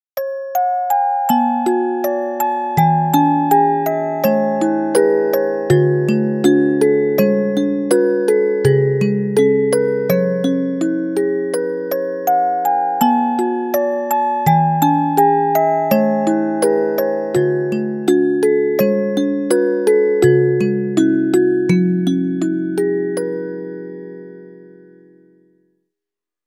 ♪適当に作ったオルゴール曲
オルゴールの仕組みについて調べて編曲したが
曲調が世界観とちょっと違う気がするなあ。